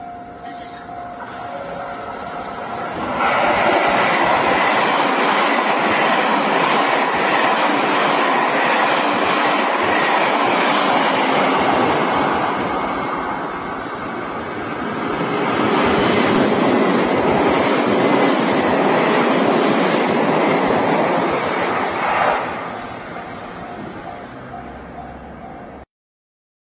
名古屋方面からと、長野方面からの「WVしなの」が安茂里駅で高速ですれ違います。